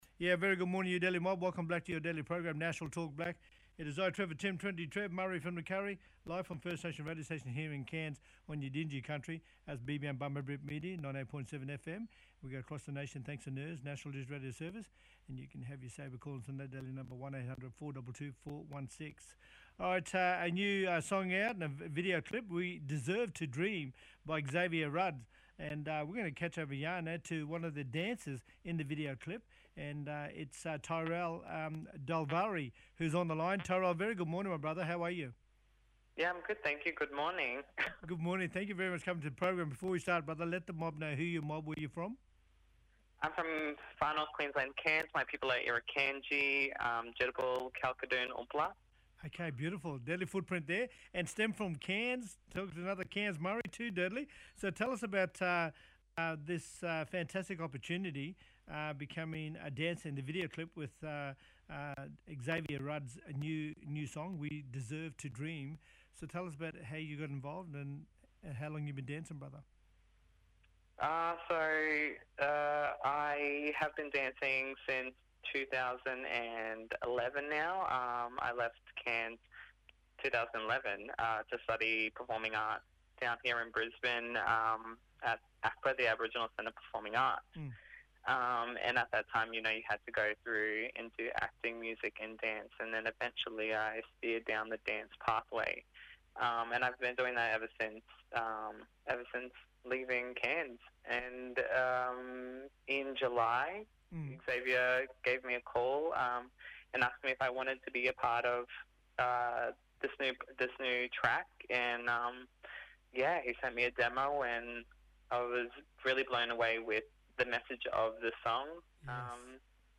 Mayor Keri Tamwoy, Mayor of Aurukun talking about New Aurukun Airport to service community into the future.
Hon Robin Chapple, talking about Country left scarred. Traditional Owners are calling on the WA Government to clarify plans for the clean-up of asbestos contamination in and around what was the town of Wittenoom.